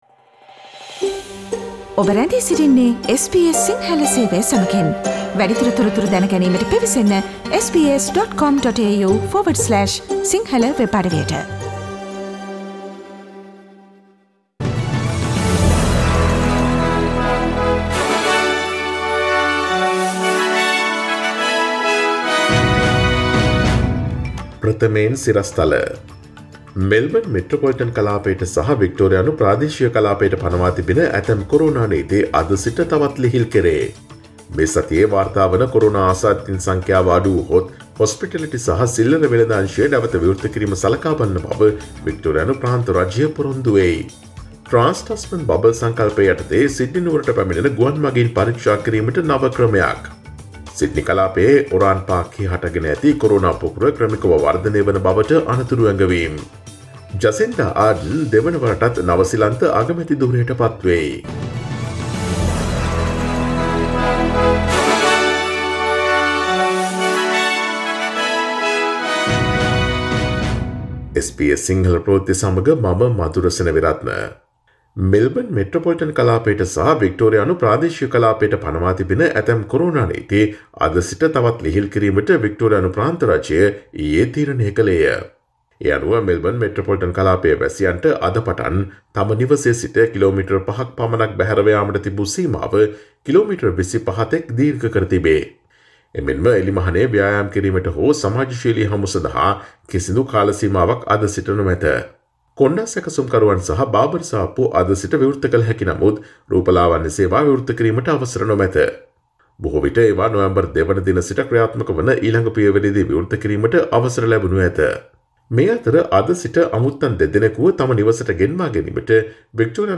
Daily News bulletin of SBS Sinhala Service: Monday 19 October 2020